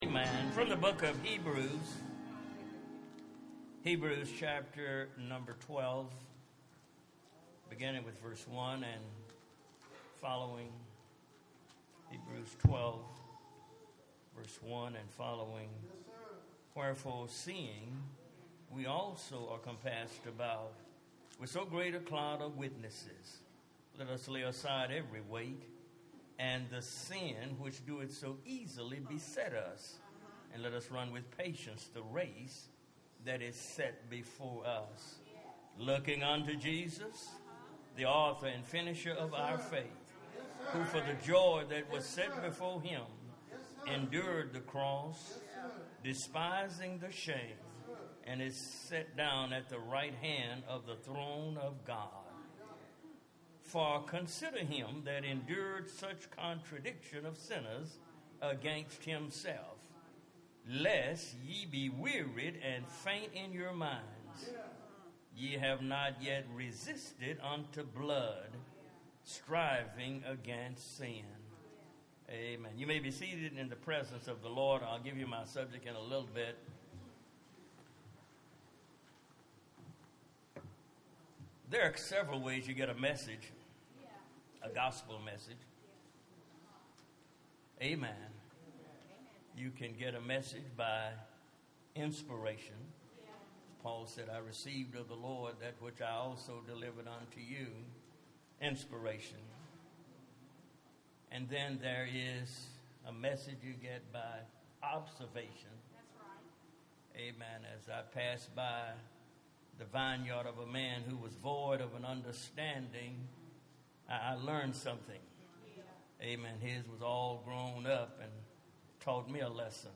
Bible Text: Heb. 12:1-4 | Preacher